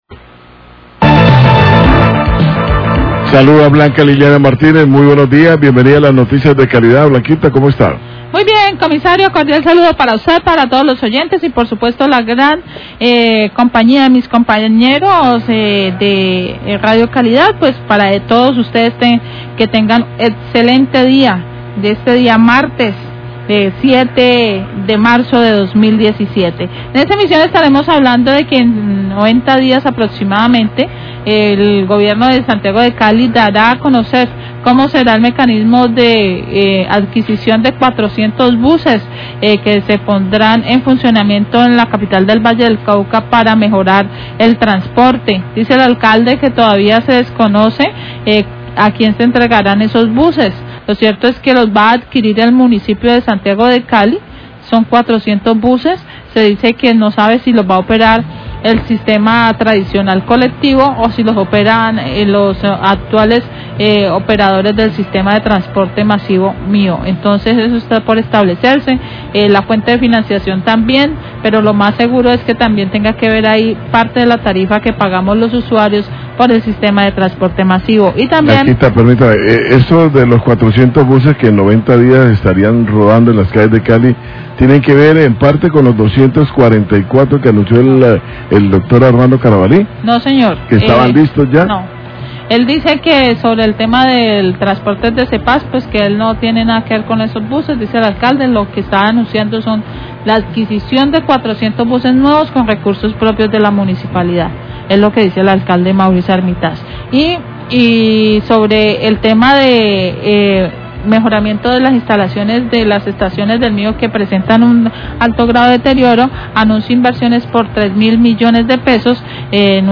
Radio
NOTICIAS DE CALIDAD